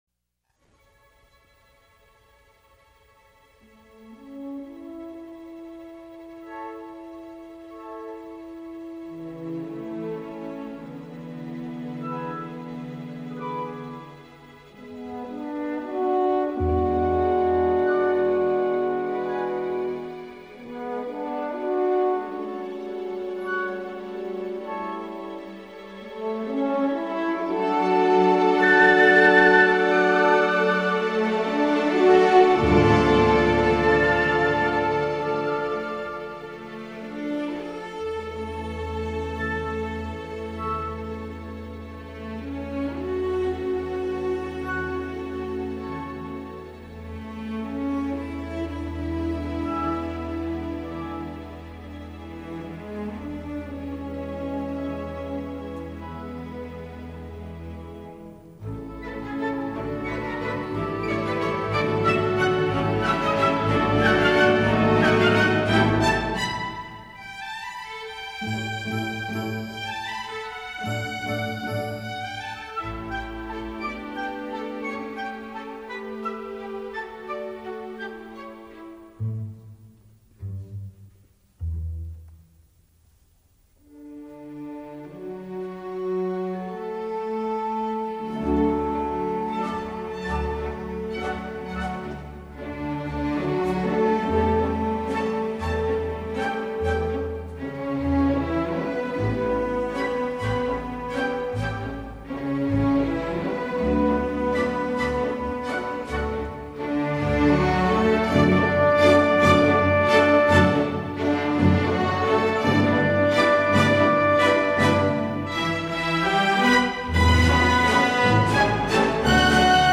El vals es la de la llama violeta
El vals, que tiene un ritmo de tres cuartos, es el ritmo de la , del corazón de Dios, y también es el ritmo de la , de manera que el vals armoniza el corazón, incrementa el flujo de las energías de nuestros y ayuda a que la se equilibre, se intensifique, se expanda y resplandezca.